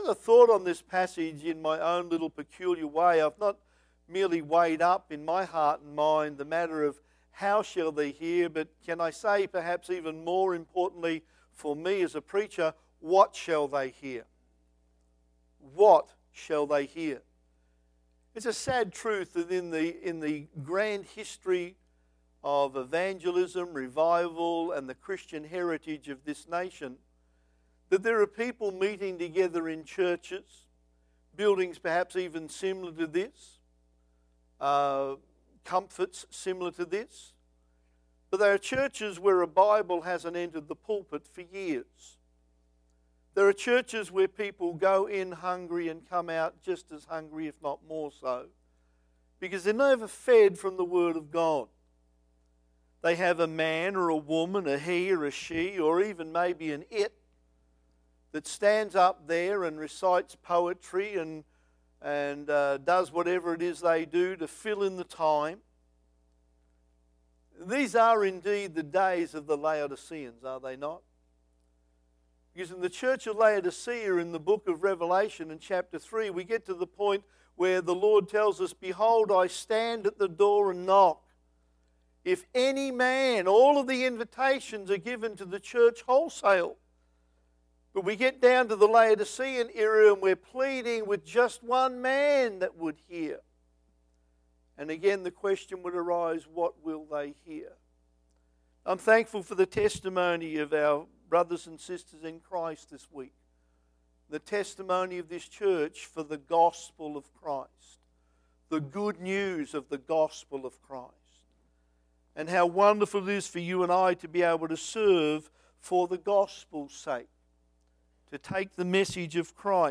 Passage: Romans 8 Service Type: Sunday PM